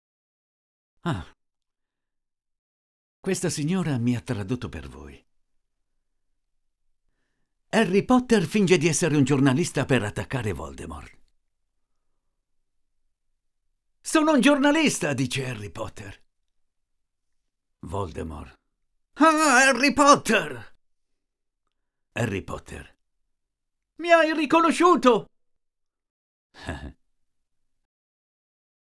电影对白